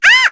Luna-Vox_Dead_kr_01.wav